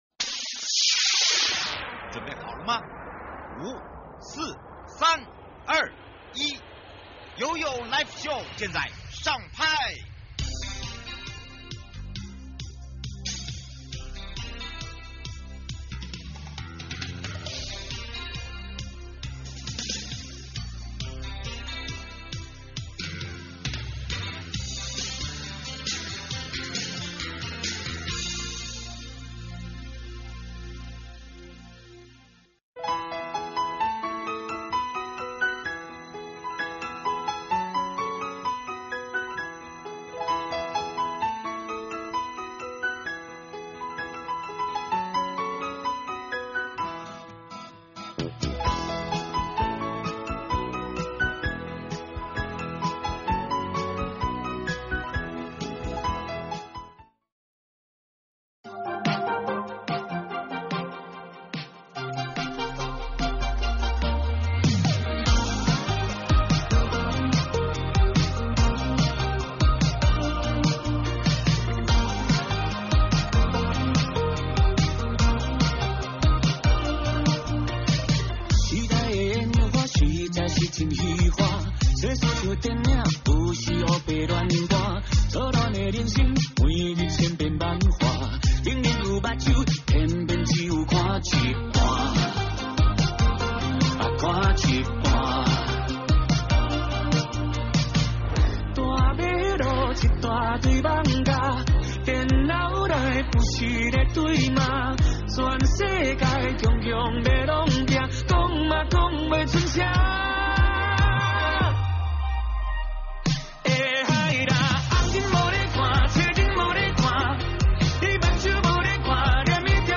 受訪者